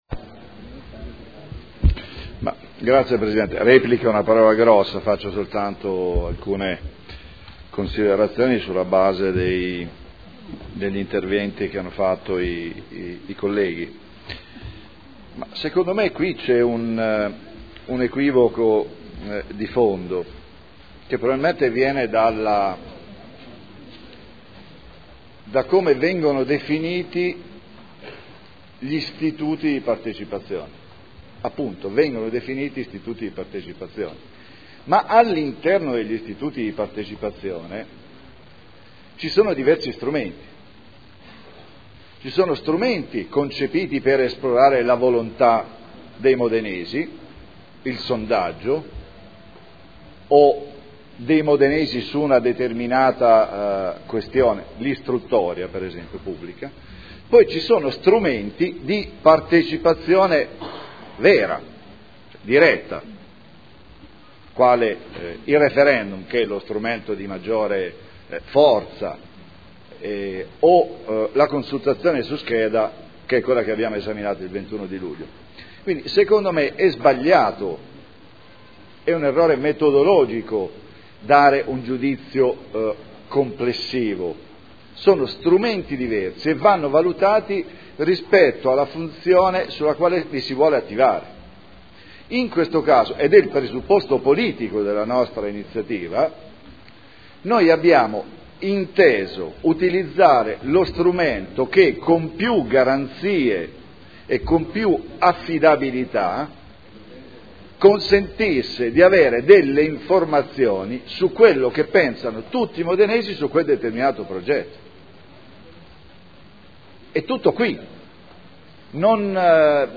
Paolo Trande — Sito Audio Consiglio Comunale
Seduta del 05/09/2011. Dibattito su Consultazione popolare ai sensi dell’art. 8 del Regolamento degli Istituti di Partecipazione del Comune di Modena sul progetto di ristrutturazione di Piazza Matteotti mediante sondaggio (Conferenza Capigruppo del 5 settembre 2011)